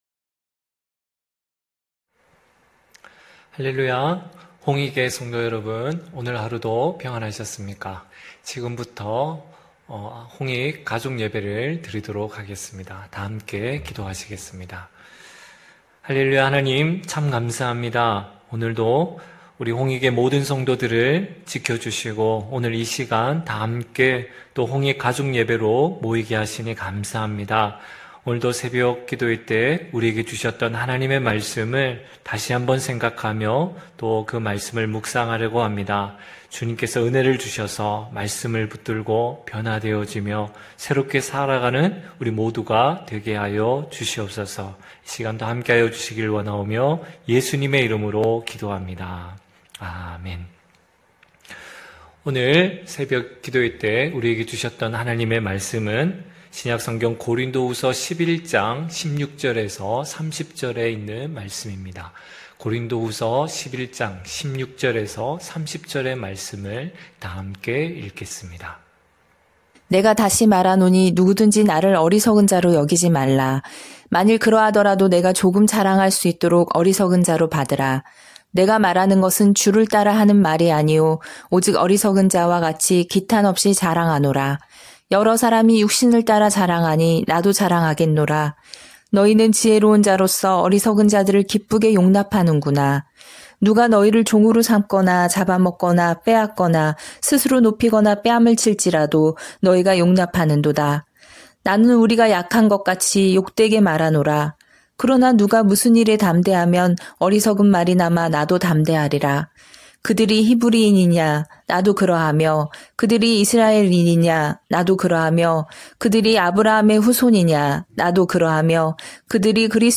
9시홍익가족예배(10월10일).mp3